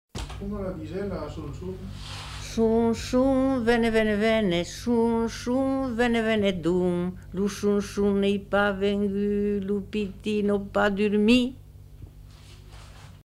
Aire culturelle : Périgord
Lieu : Saint-Rémy-de-Gurson
Genre : chant
Effectif : 1
Type de voix : voix de femme
Production du son : chanté
Classification : som-soms, nénies